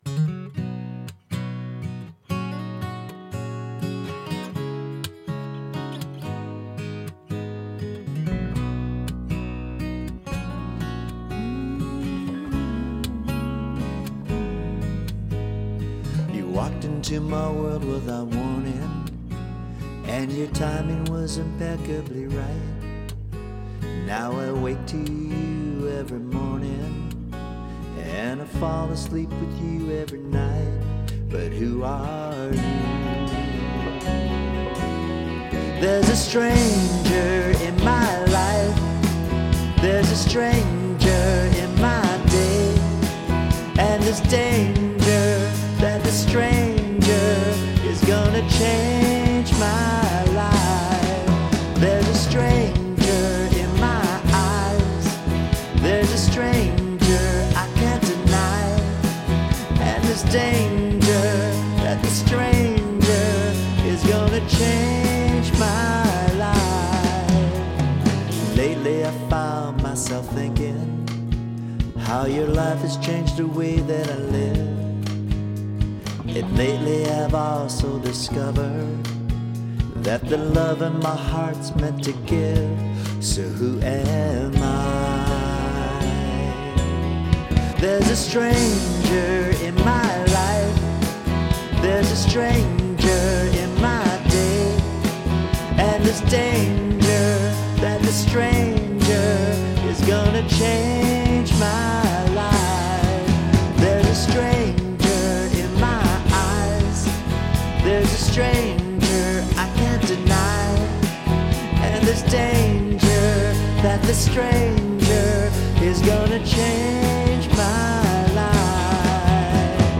Key of B - Track with Reference Vocal